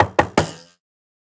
fletching_table1.ogg